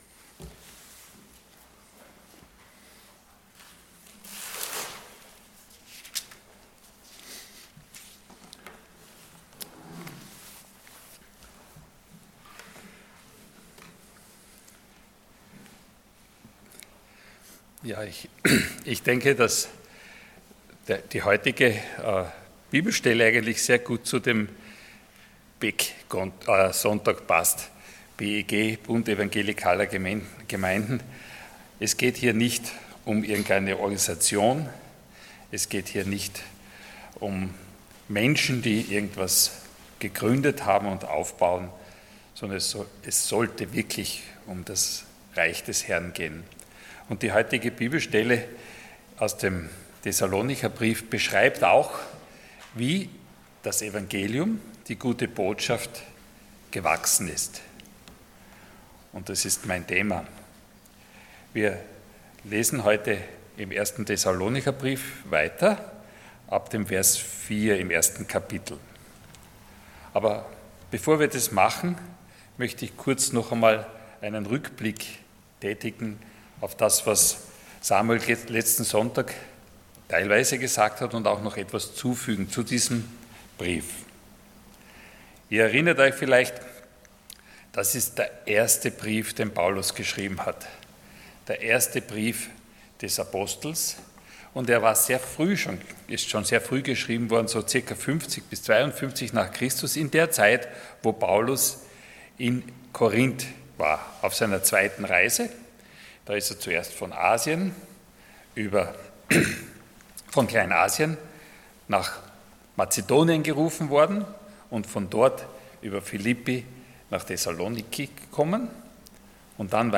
Passage: 1 Thessalonians 1:4-10 Dienstart: Sonntag Morgen